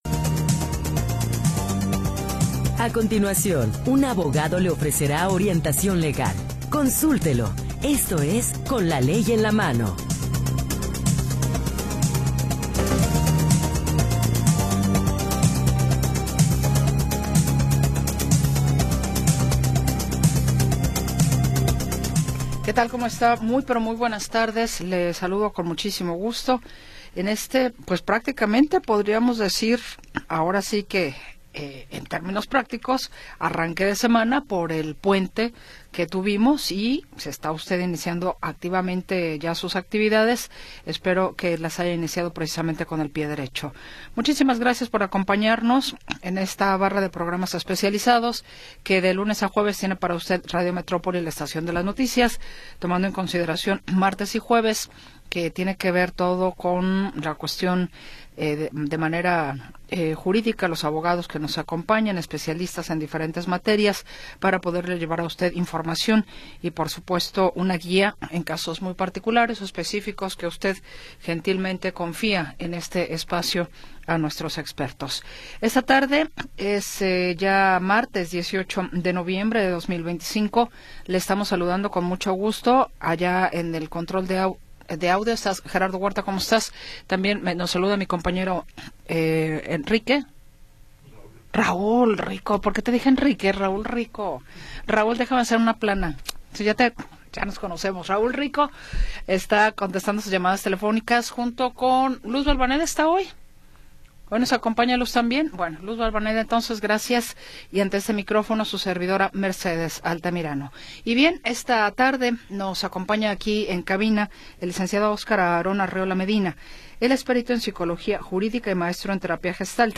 Noticias y entrevistas sobre sucesos del momento